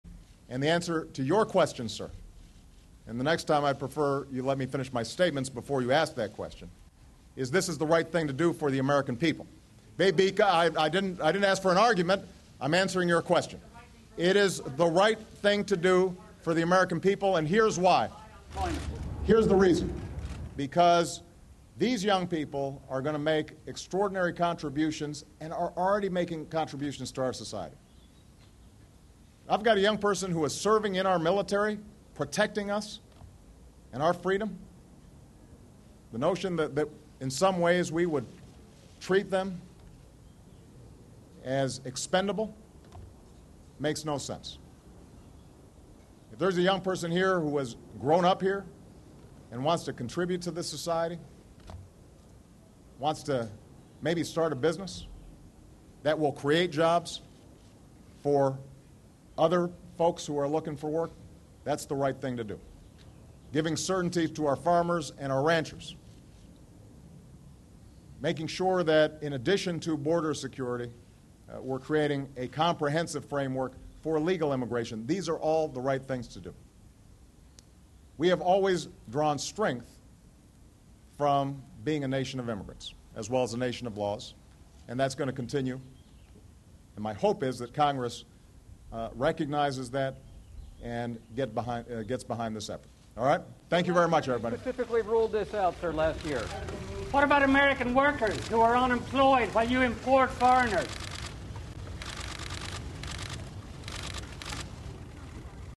The president chastised the conservative site's reporter.